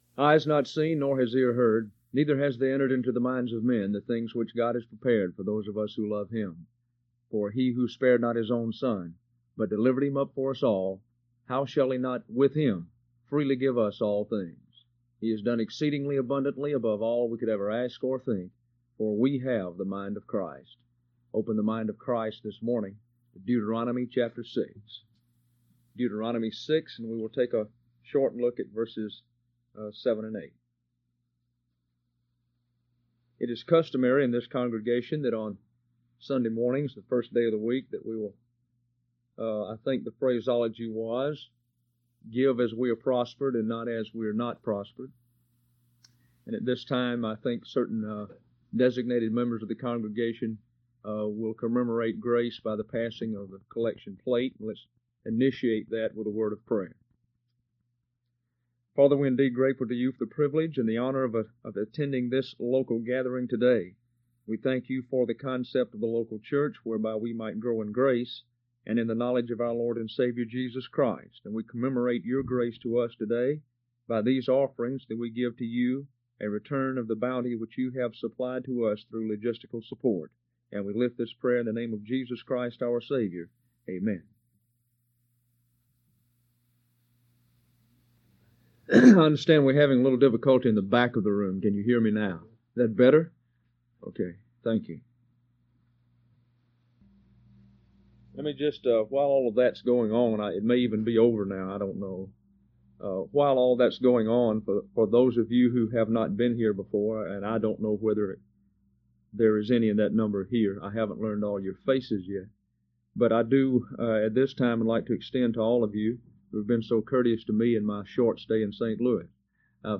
Trial Sermon: Operation Mnemoneuo: Lesson 2